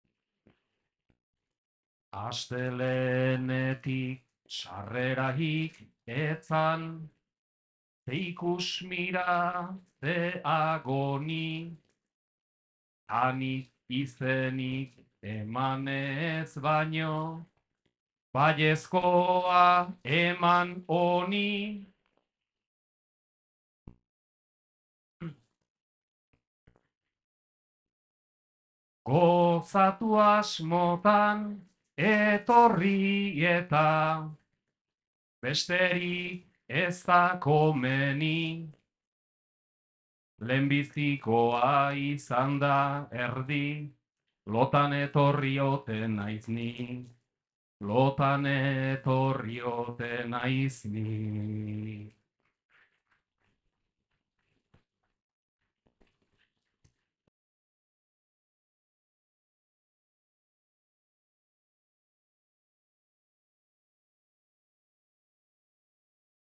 2024-04-26 Zestoa
Sailkapen saioa.